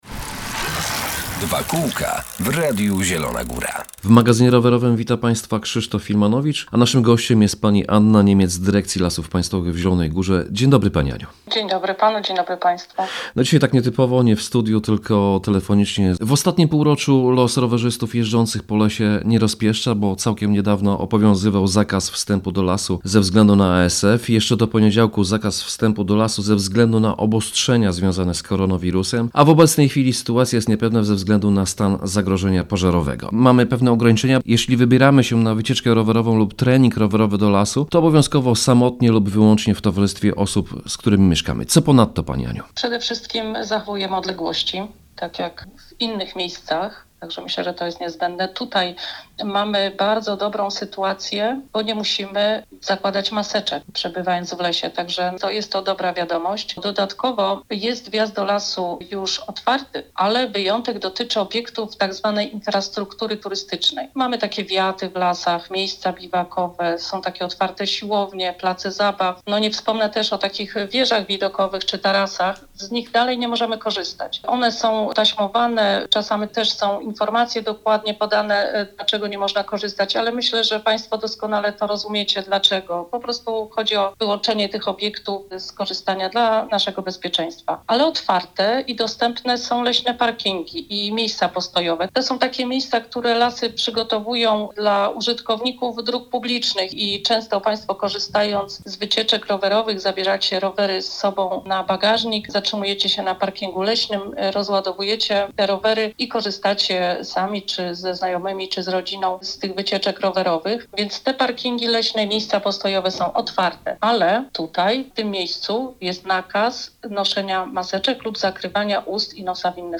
2Kółka – magazyn rowerowy